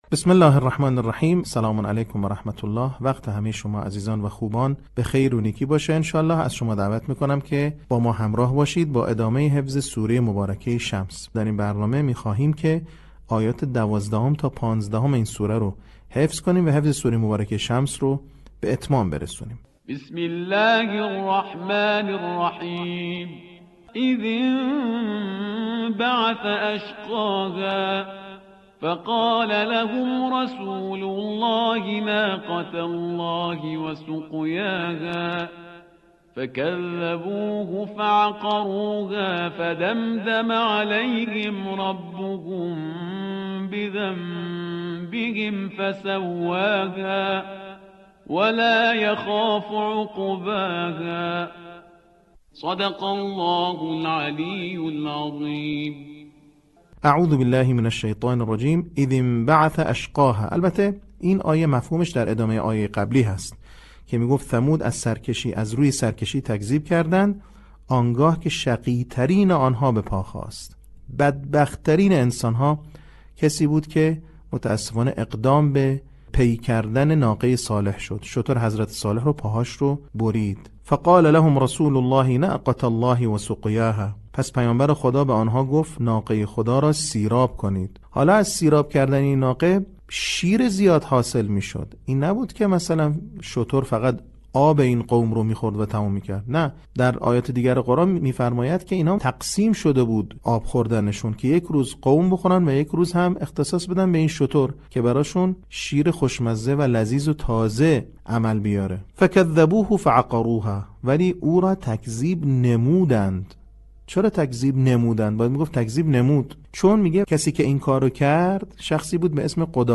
آموزش قرآن